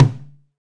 BR Tom Hi.WAV